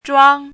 怎么读
zhuāng
zhuang1.mp3